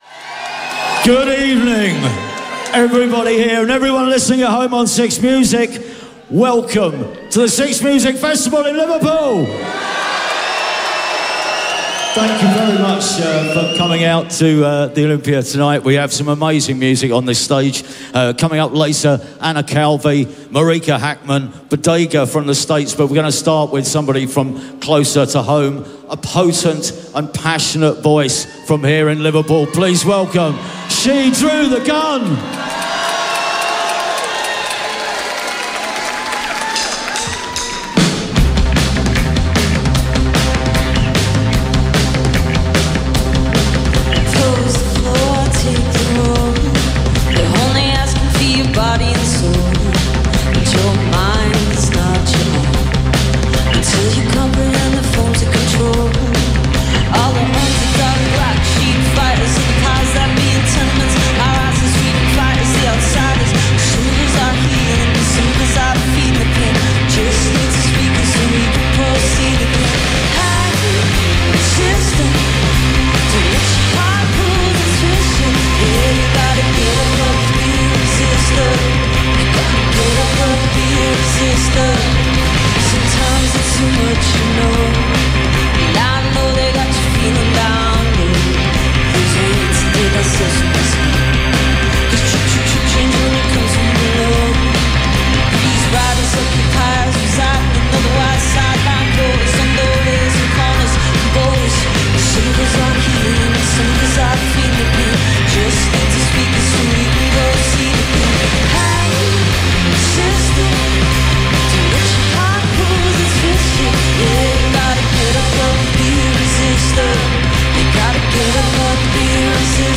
live At 6 Music Festival, Liverpool